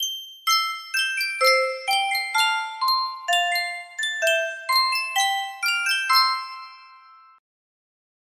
Sankyo Miniature Music Box - WYAIWYM AXR music box melody